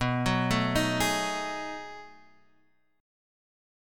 B13 chord